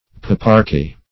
Search Result for " paparchy" : The Collaborative International Dictionary of English v.0.48: Paparchy \Pa"par*chy\, n. [L. papa bishop + -archy.] Government by a pope; papal rule.